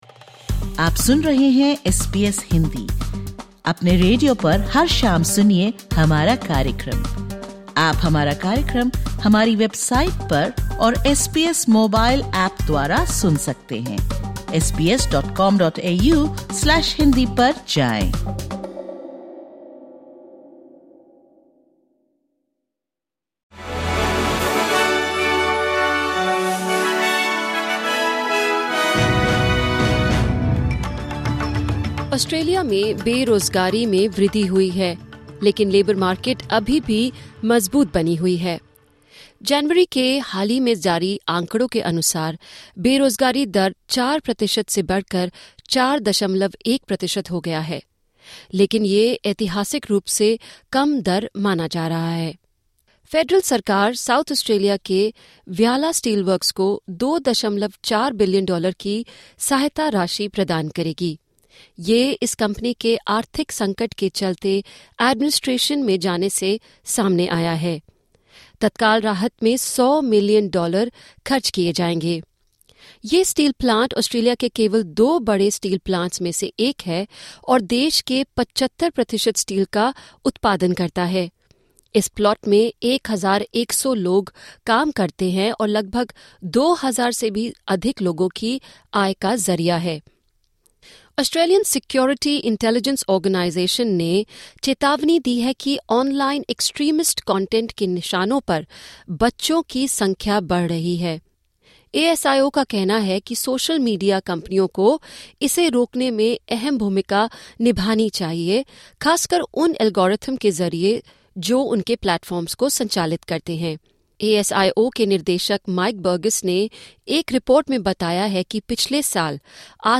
सुनें ऑस्ट्रेलिया और भारत से 20/02/2025 की प्रमुख खबरें।